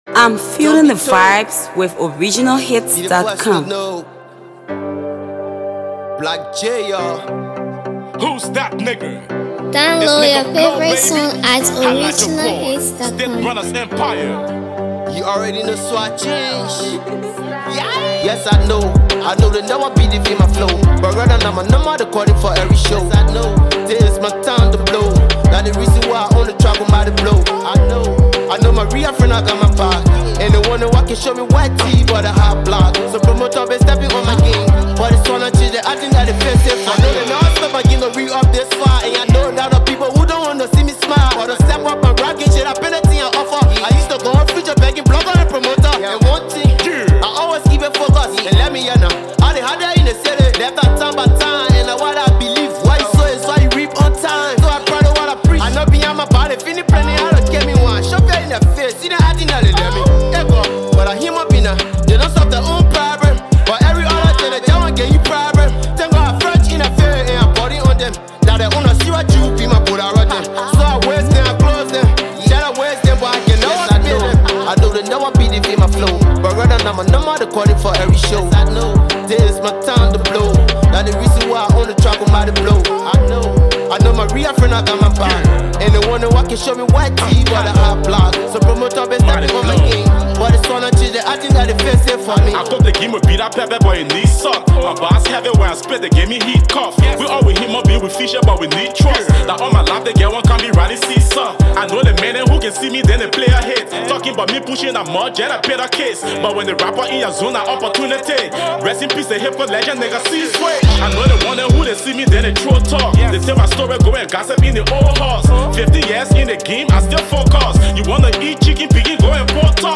Hipco